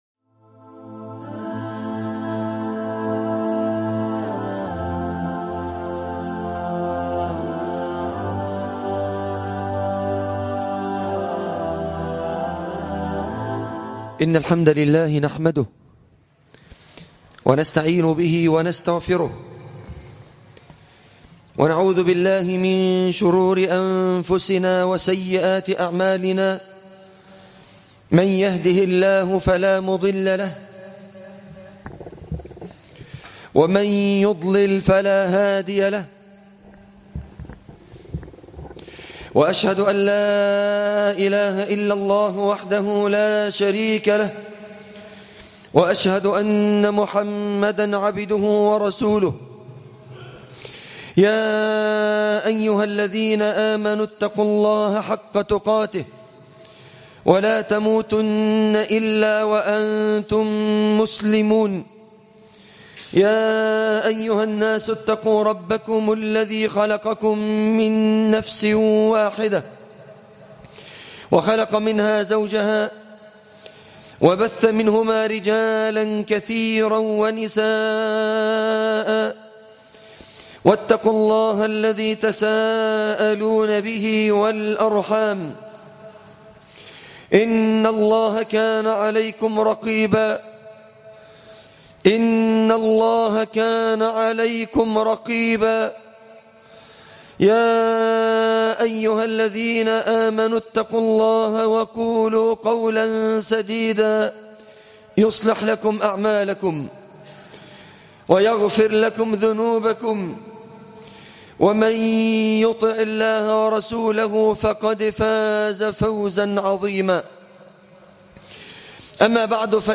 تفاصيل المادة عنوان المادة الله أكبر || خطبة الجمعة تاريخ التحميل الثلاثاء 23 يناير 2024 مـ حجم المادة 25.87 ميجا بايت عدد الزيارات 392 زيارة عدد مرات الحفظ 156 مرة إستماع المادة حفظ المادة اضف تعليقك أرسل لصديق